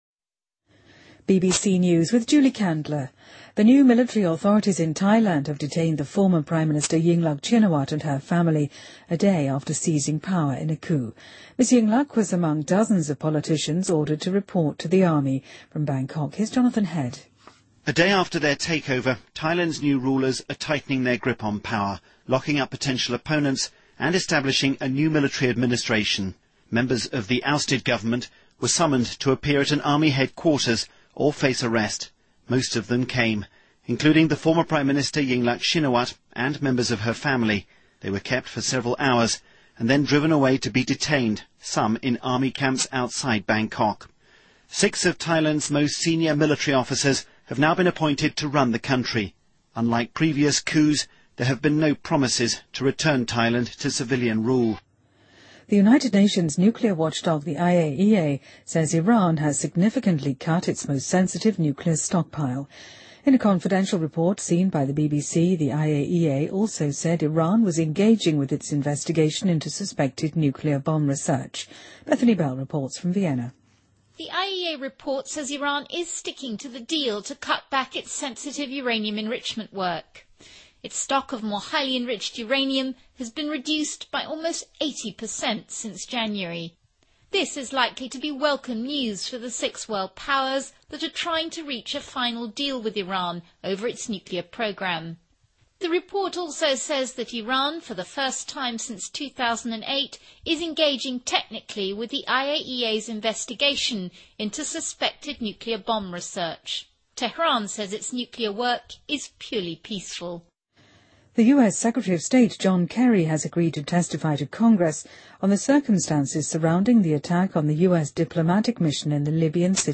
BBC news,泰国军事当局拘留前总理英拉和她的家人